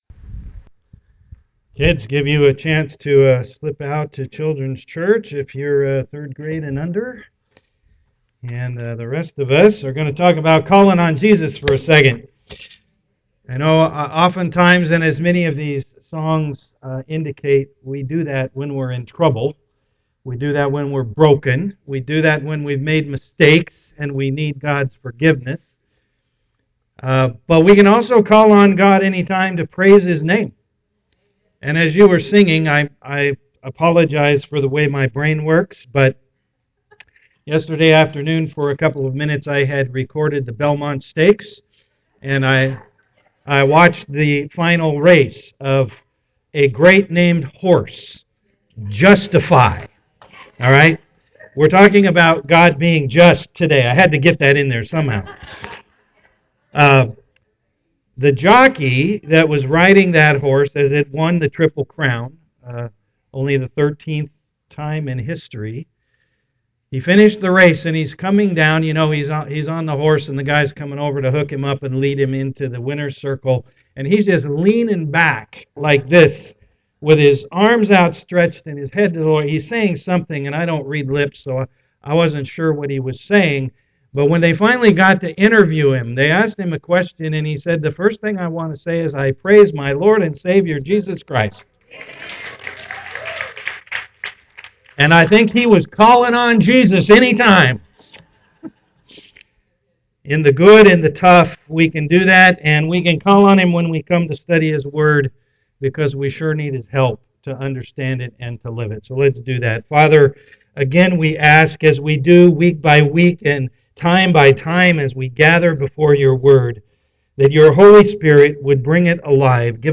Know God Service Type: am worship Download Files Notes Bulletin Topics